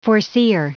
Prononciation du mot foreseer en anglais (fichier audio)
Prononciation du mot : foreseer